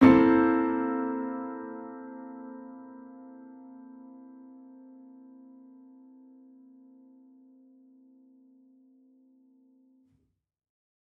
Index of /musicradar/gangster-sting-samples/Chord Hits/Piano
GS_PiChrd-C6min7.wav